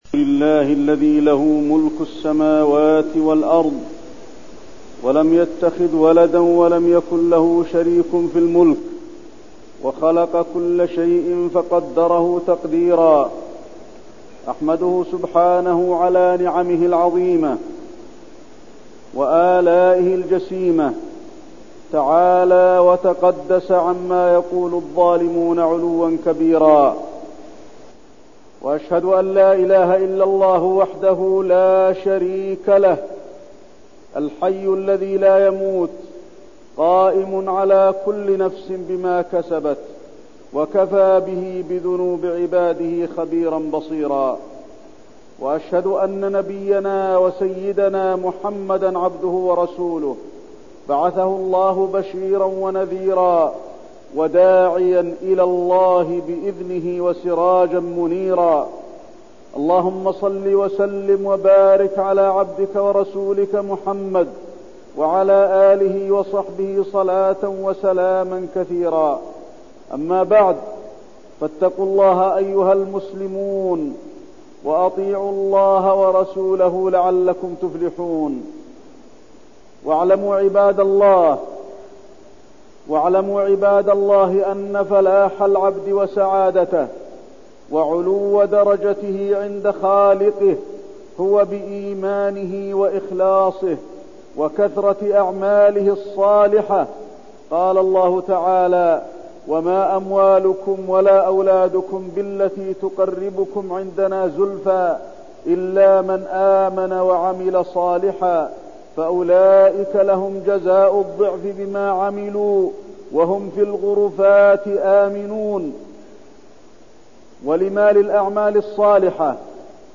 تاريخ النشر ٩ شوال ١٤١٠ هـ المكان: المسجد النبوي الشيخ: فضيلة الشيخ د. علي بن عبدالرحمن الحذيفي فضيلة الشيخ د. علي بن عبدالرحمن الحذيفي المسابقة إلى الخيرات